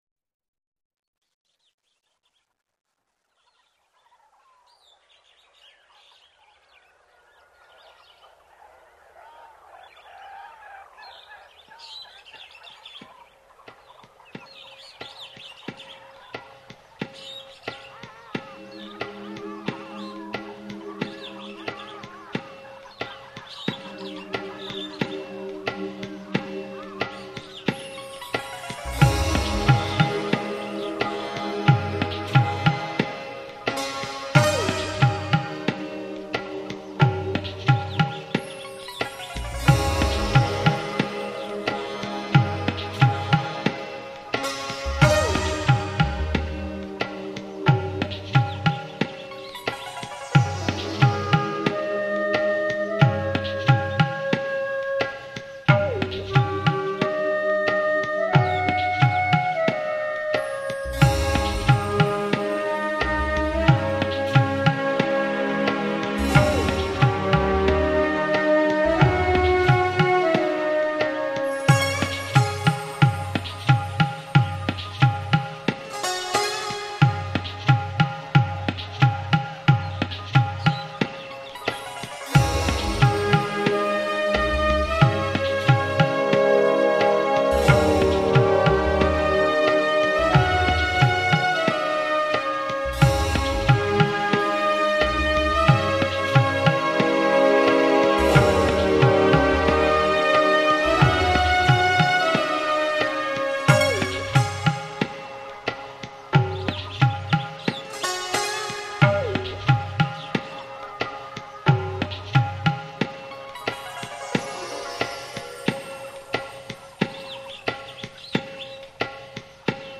Vivaldi - Guitar Concerto in D Major, RV 93 - Largo (открыта)